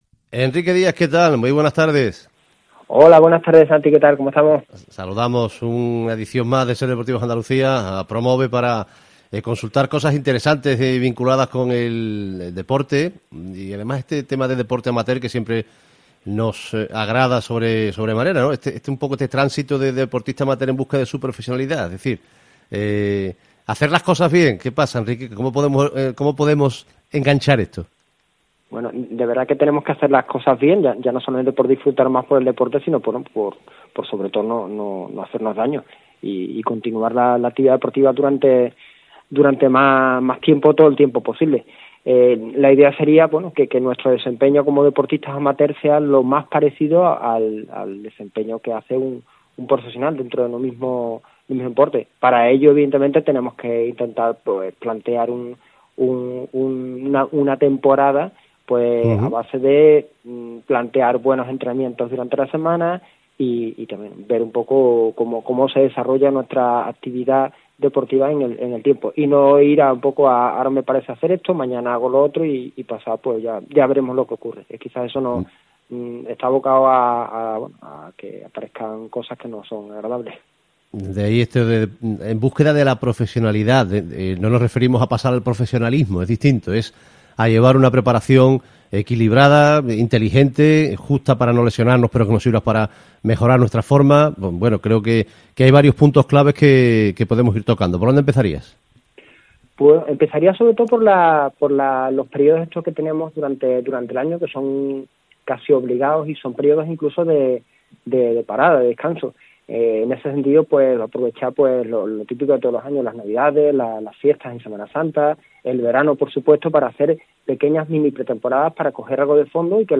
Emitido el domingo 16 de enero de 2022, en Cadena SER.